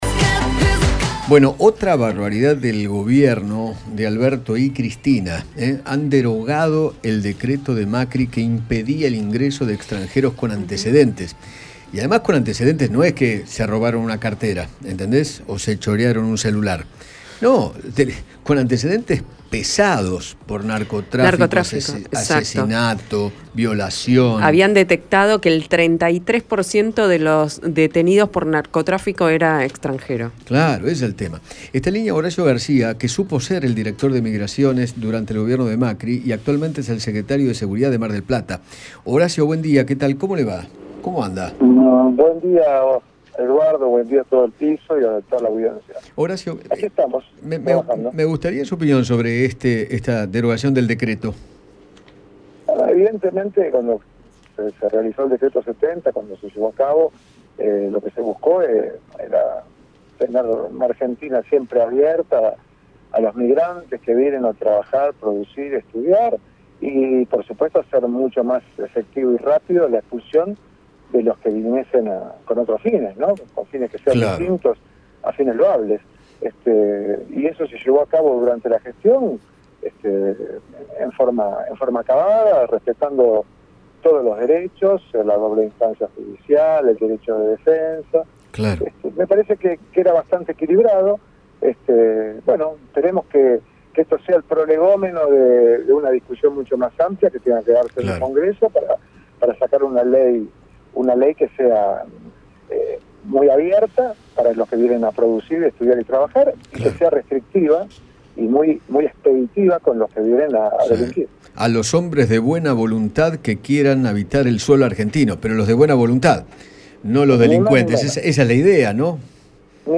Horacio Garcia, ex director de migraciones, dialogó con Eduardo Feinmann sobre la decisión del Gobierno de anular el decreto de la gestión de Macri, que restringia el ingreso de migrantes con antecedentes penales.